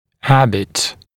[‘hæbɪt][‘хэбит]привычка, вредная привычка